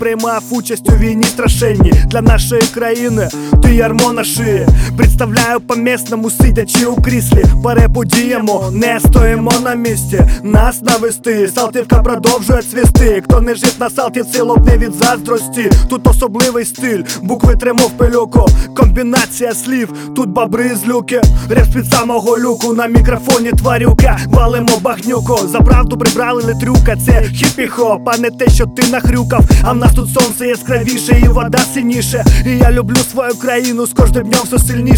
Hip-Hop Rap
Жанр: Хип-Хоп / Рэп / Украинские